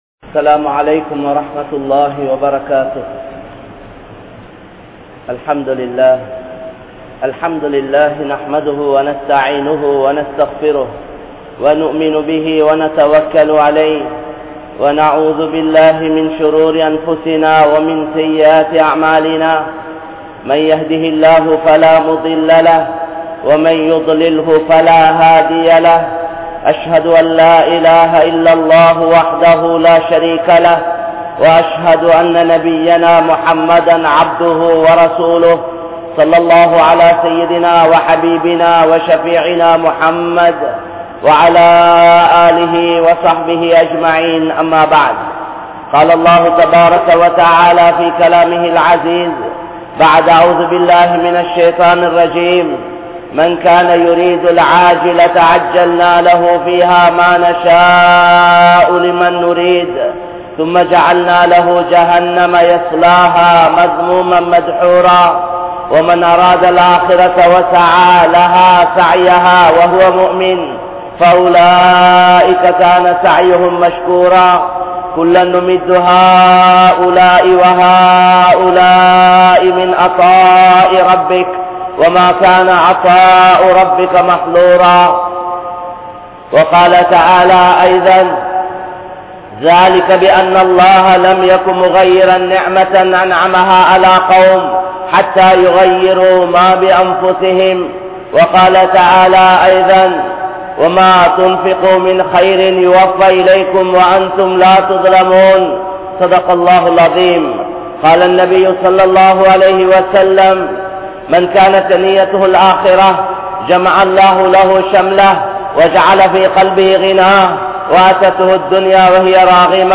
Allah`vukkaaha Vaalungal (அல்லாஹ்வுக்காக வாழுங்கள்) | Audio Bayans | All Ceylon Muslim Youth Community | Addalaichenai
Grand Jumua Masjith